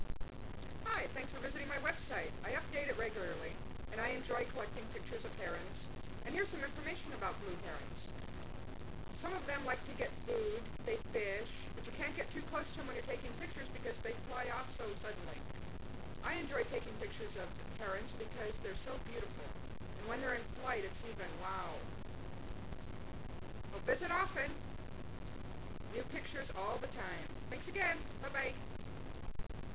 THE GREAT BLUE HERON
blueheron.wav